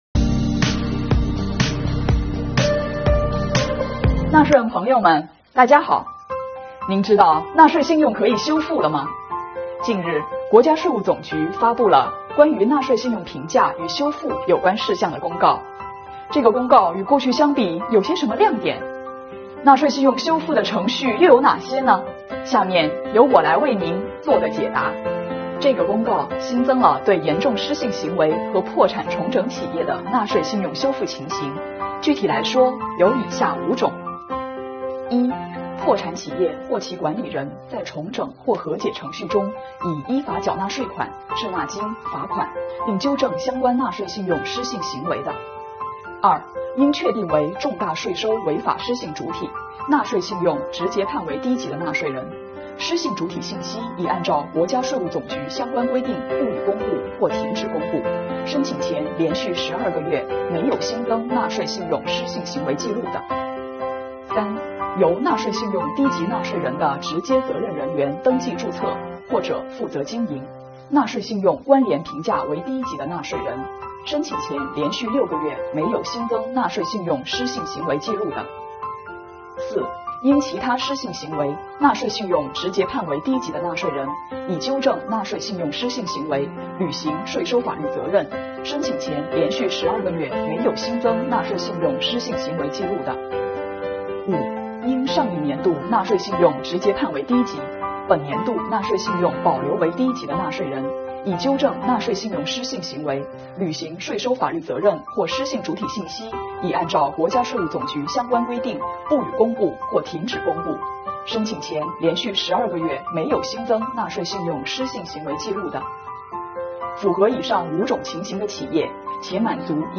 您知道纳税信用可以修复了吗?近日，国家税务总局发布了《关于纳税信用评价与修复有关事项的公告》，自2022年1月1日起施行。此次发布的《公告》与过去相比有什么亮点?纳税信用修复的程序又有哪些呢?下面，请跟着《税问我答》，让主播为您一一解答吧!